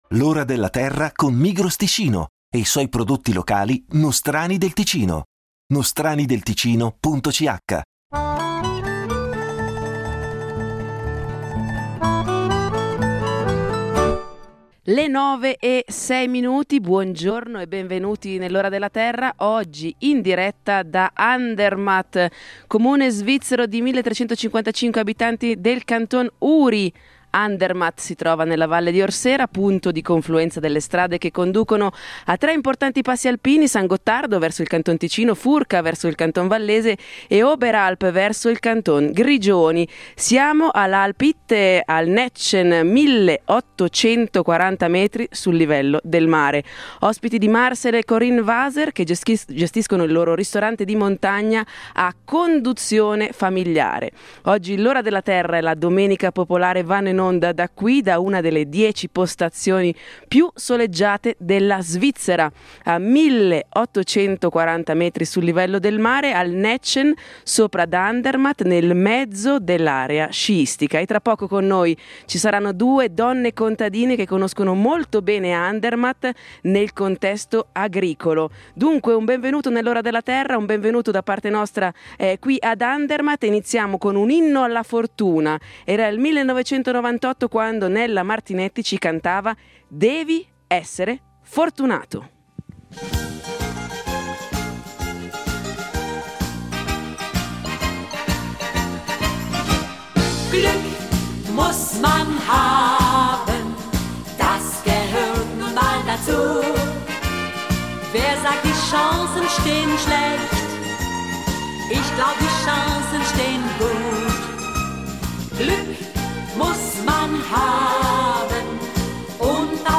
In diretta dall’Alp-Hittä Nätschen, a 1840 metri sul livello del mare.
Naturalmente non mancheranno gli esperti del programma, collegati dallo studio di Comano, pronti a rispondere alle vostre domande da casa.